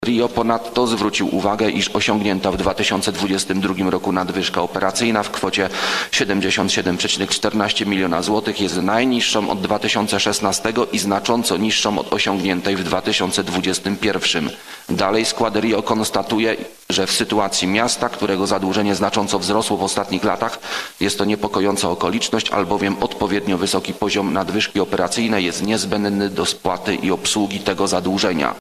W klubowym wystąpieniu radnych Prawa i Sprawiedliwości wskazana na kwoty odnoszące się do zeszłorocznego budżetu, w tym do zadłużenia miasta i wielkości nadwyżki operacyjnej. Referował radny Konrad Łoś.